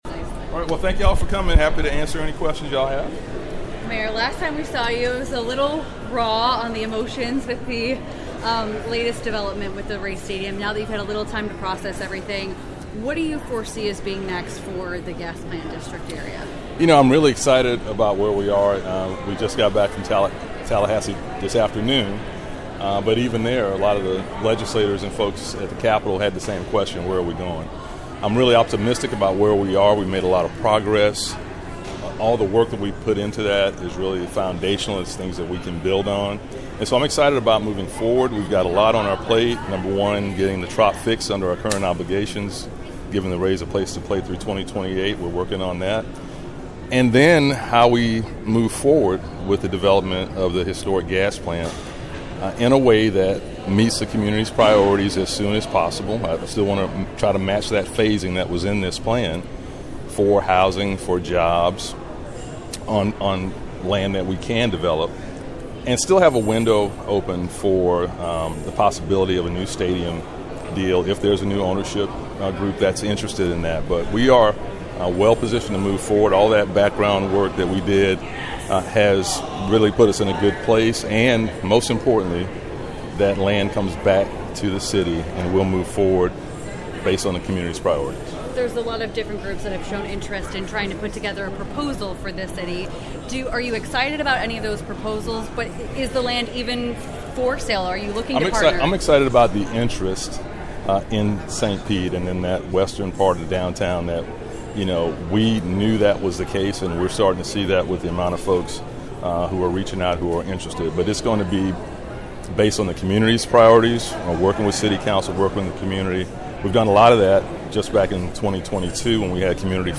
City of St. Petersburg: Mayor Kenneth Welch Press Conference 3-26-25
RadioStPete aired Mayor Ken Welch's press conference 3/26.